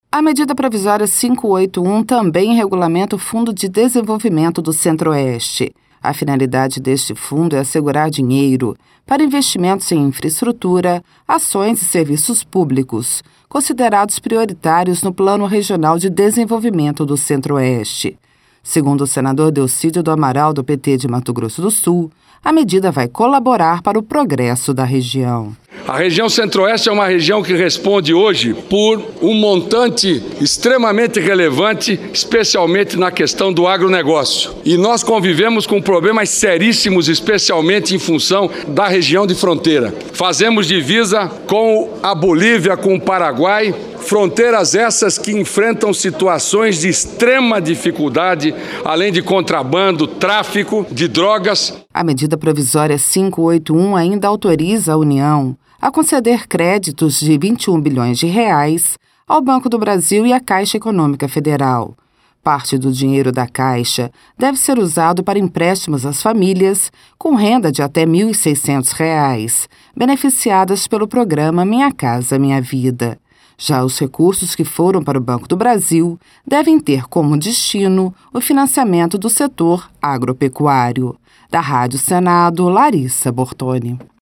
Senador Delcídio do Amaral